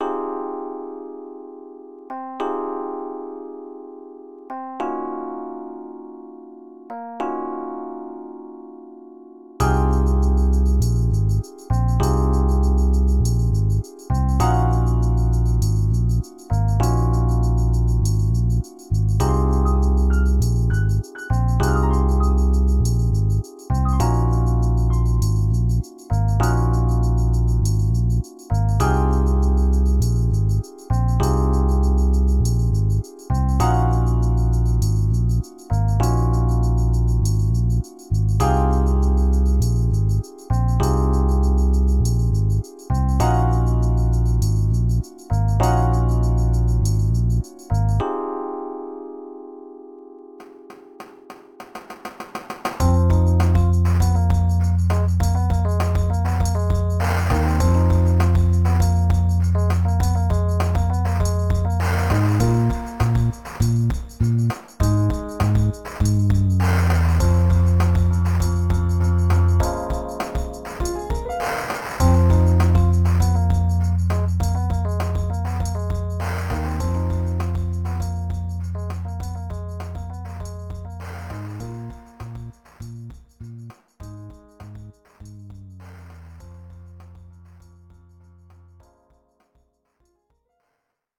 It features piano, rhodes piano, and percussion. It was written, recorded, and mastered in Reason 2.5, using various refills.